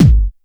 Kick_53.wav